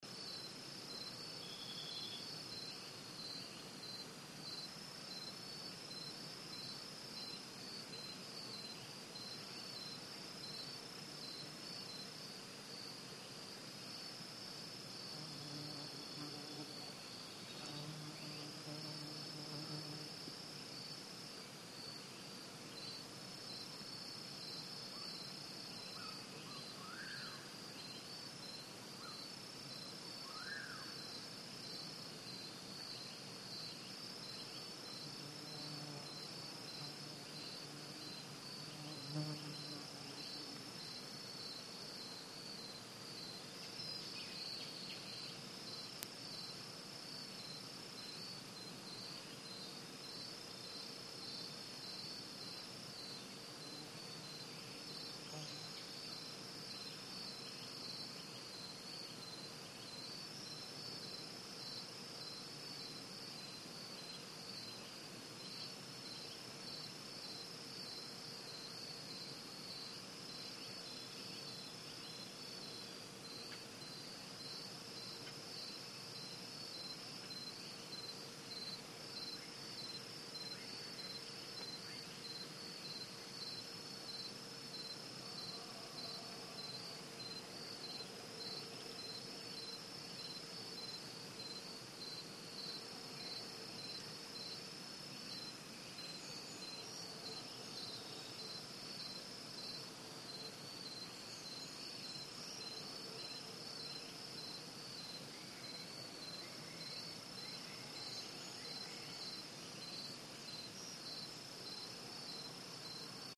Belize jungle at night, mostly crickets